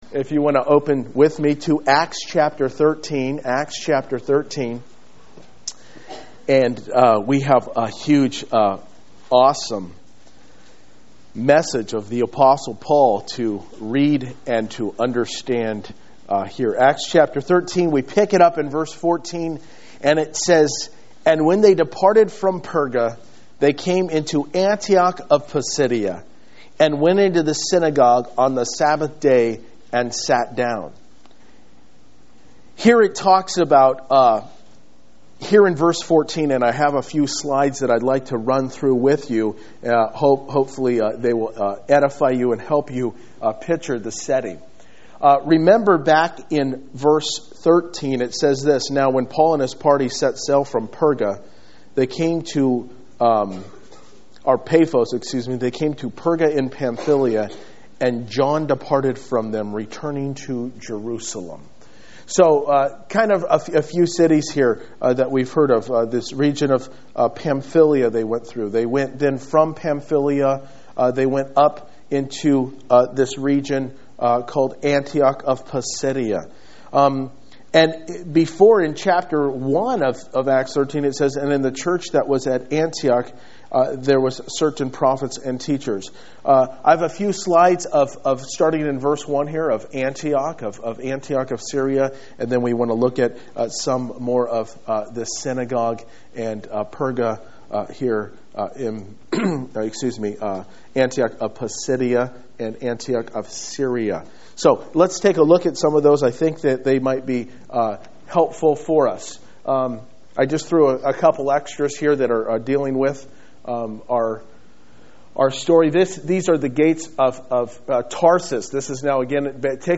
Sermon at Antioch in Pisidia